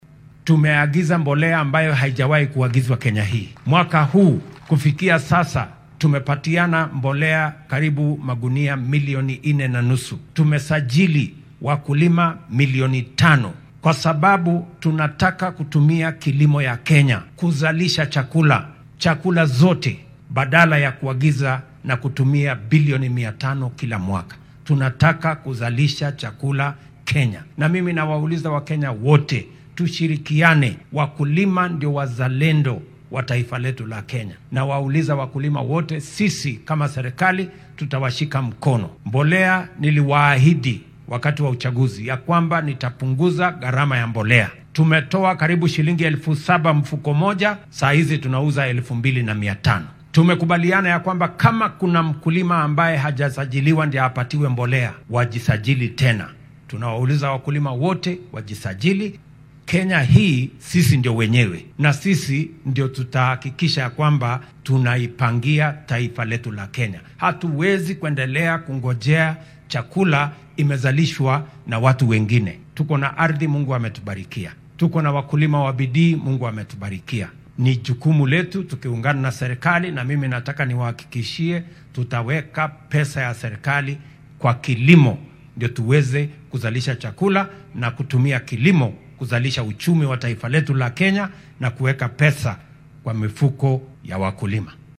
Waxaa uu hoosta ka xarriiqay in maamulkiisa uu mudnaanta koowaad siinaya la tacaalidda cunno yarida. Xilli uu ku sugnaa magaalada Laare ee deegaan baarlamaneedka Waqooyiga Igembe ee ismaamulka Meru ayuu sheegay in dowladdu ay sii wadi doonto in beeralayda laga caawiyo helidda agabka wax soo saarka iyo suuqgeynta dalagyada uga soo go’o beeraha.